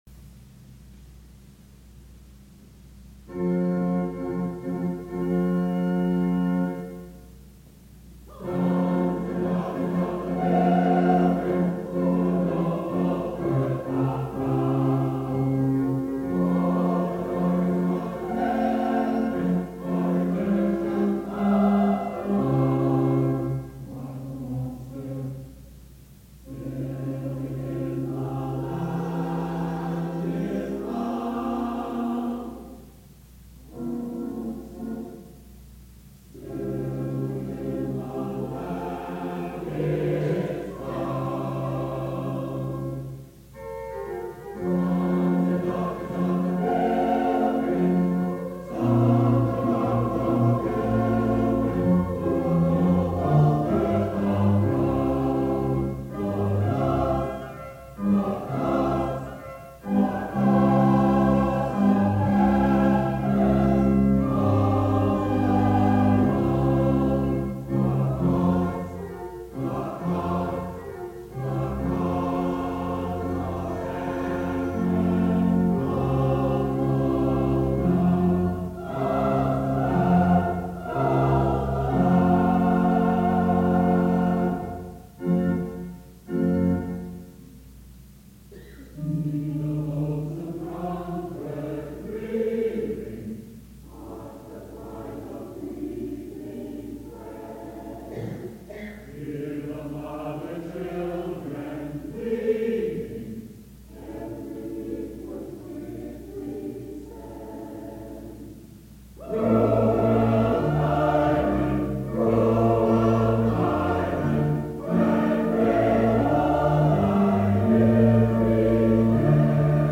Sons and Daughters of the Pilgrims (ca. 1870) - Temperance hymn